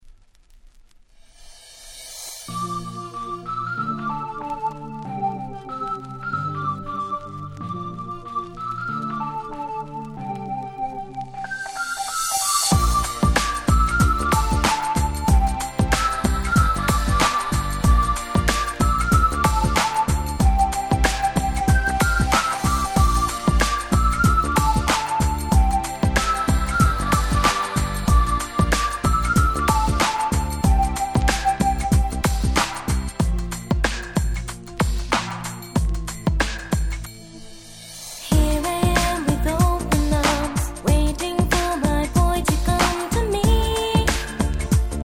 鉄板キャッチーR&B！！
どちらかと言えばDance Popに分類されるのでしょうが、Ground Beatとの相性は抜群！！
切なく幻想的なメロディーが本当に溜まりません！